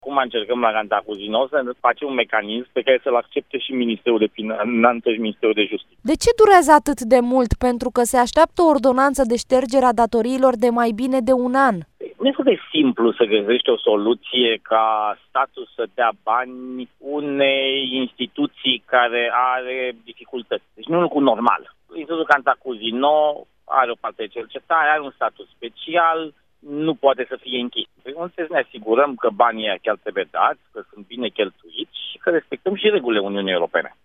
l-a întrebat pe președintele Consiliului Concurenței, Bogdan Chirițoiu, cum și când va convinge Guvernul Comisia Europeană să accepte ștergerea datoriilor Institutului Cantacuzino.